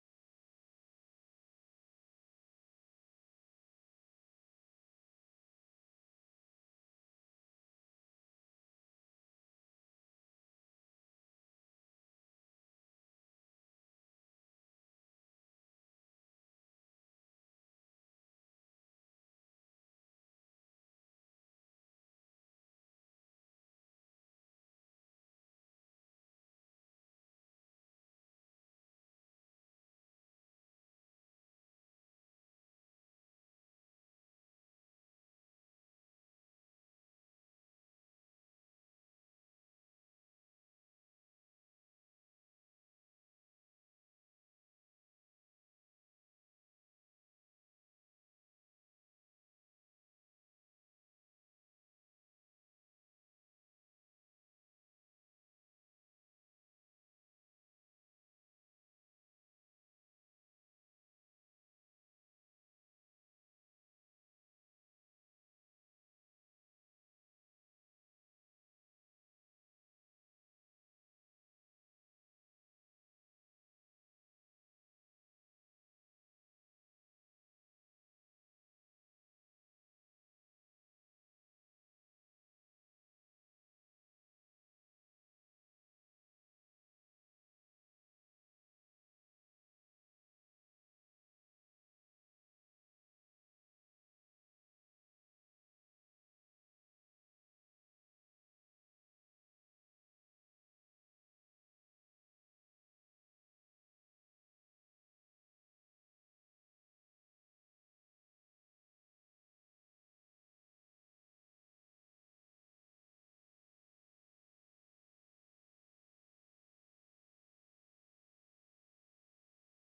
Město Varnsdorf: 25. zasedání Zastupitelstva města Varnsdorf Místo konání: Lidová zahrada, Karlova 702, Varnsdorf Doba konání: 23. června 2022 od 15:00 hod.